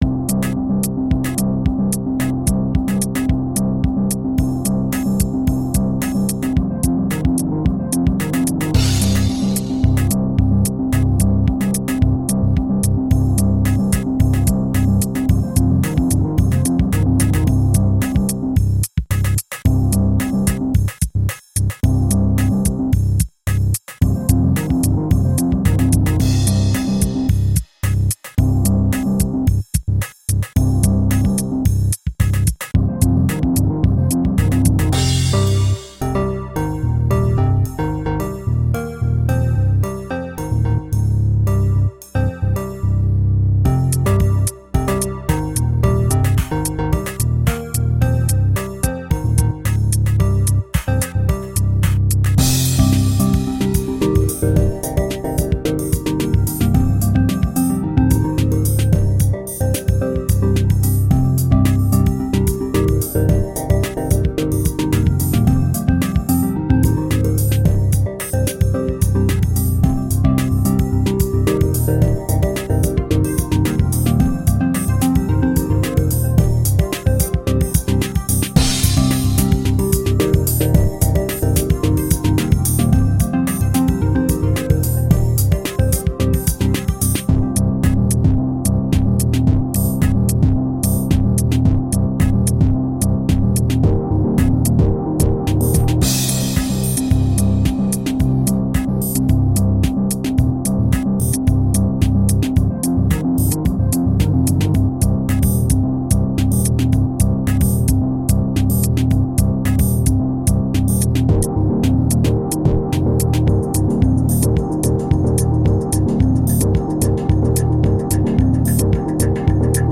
Electronica with cool house and rock elements.
Tagged as: Electronica, Rock